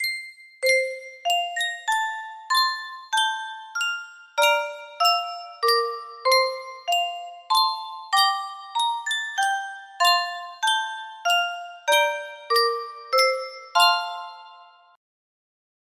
Yunsheng Music Box - Away in a Manger 6523 music box melody
Full range 60